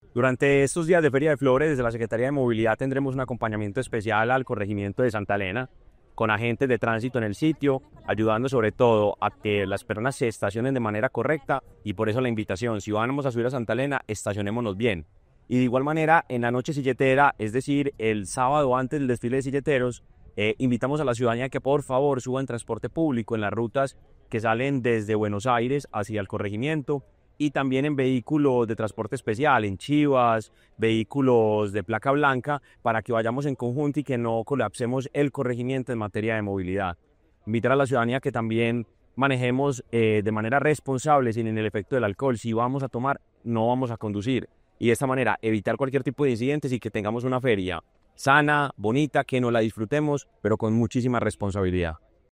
Declaraciones del secretario de Movilidad, Mateo González Benítez.